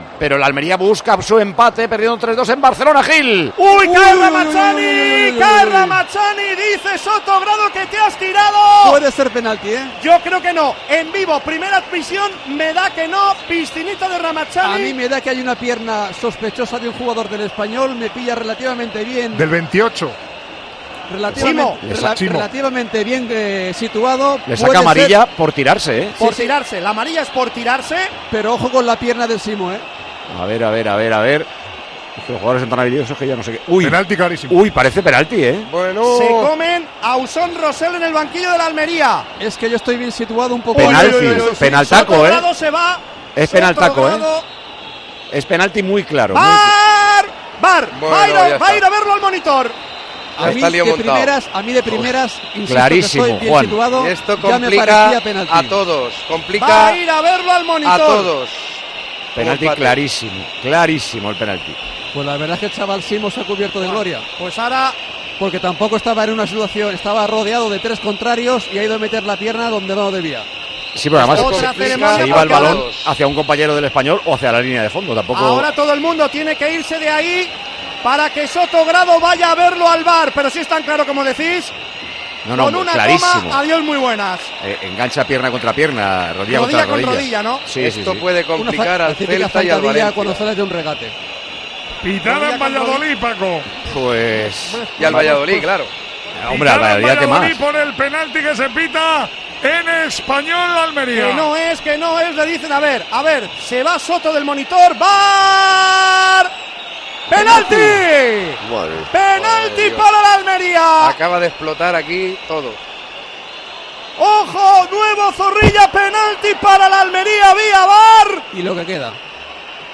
Los tertulianos del Tertulión de Tiempo de Juego repasaron los pronósticos que hicieron para la temporada 2022-23 en LaLiga Santander el pasado agosto.
Y muy pocos acertaron con sus apuestas, pero entre risas repasaron sus resultados.